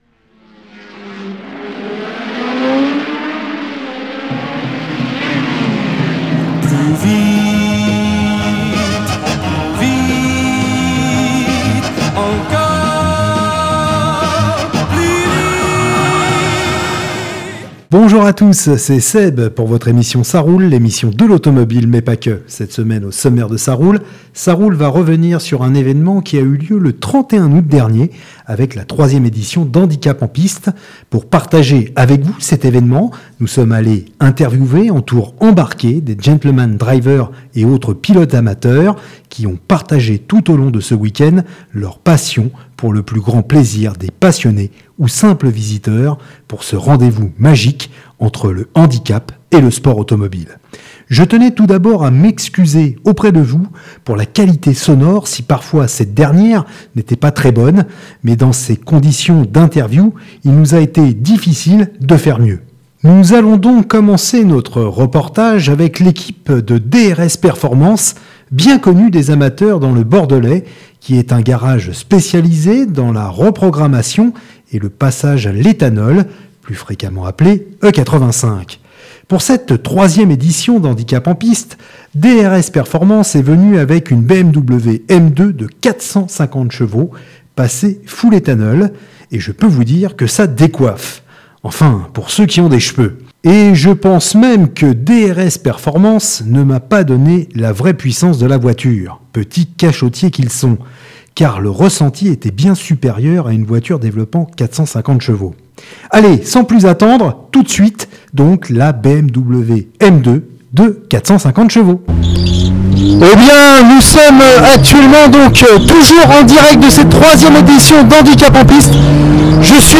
CA ROULE DU 18.09.2025 " INTERVIEWS GENTLEMAN DRIVER HANDICAP EN PISTE 2025 "